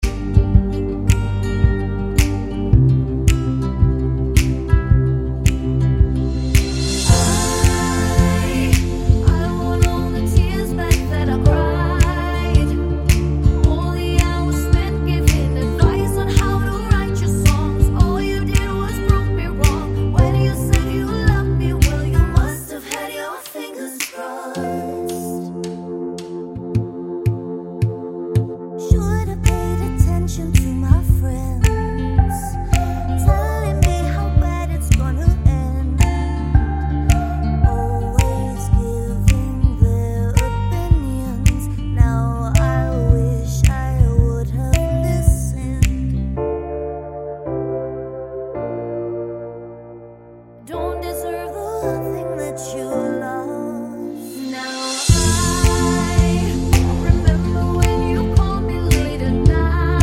Pop (2020s)